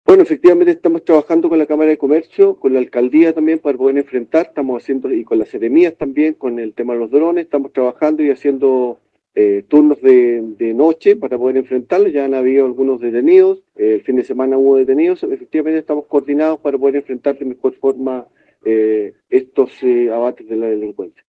También informó el general Bohle que, se ha estado trabajando con las Cámaras de Comercio en atención a solicitudes de reforzamiento de seguridad por robos a comercios, escuchemos: